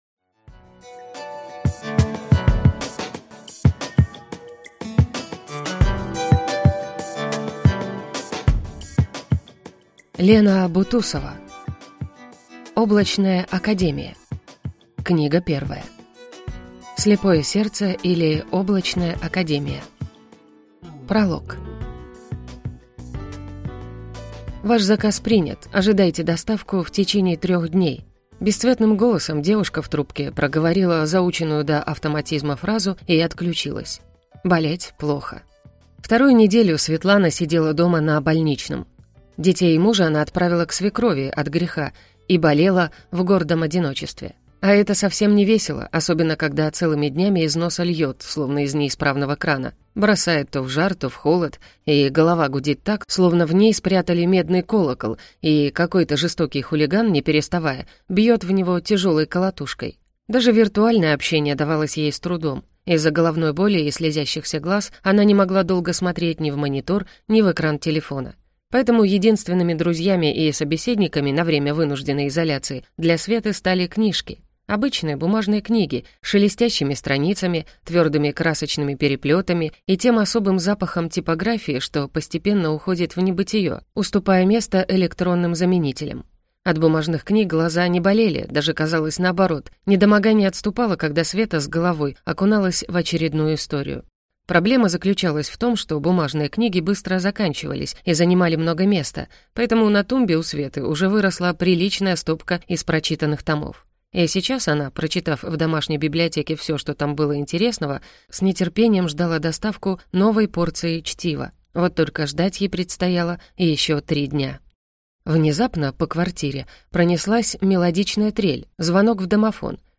Аудиокнига Слепое сердце, или Облачная Академия. Книга 1 | Библиотека аудиокниг